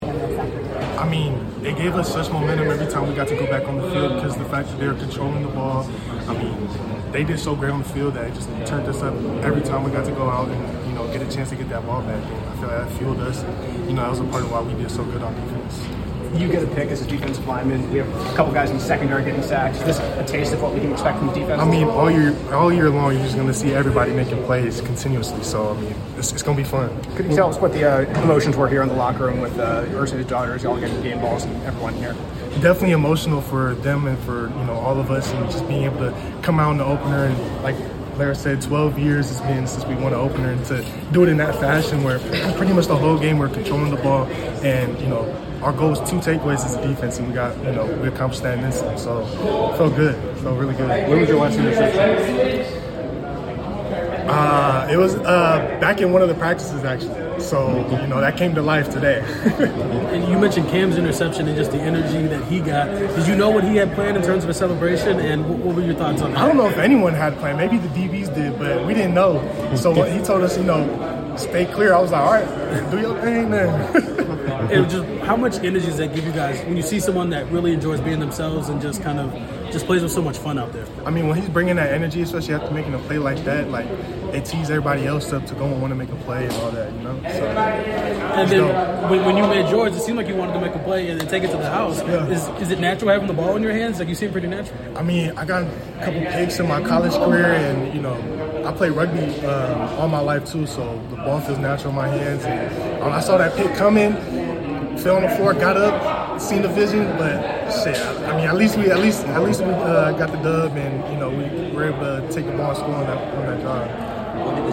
09-07-25 Indianapolis Colts Defensive Lineman Laiatu Latu Postgame Interview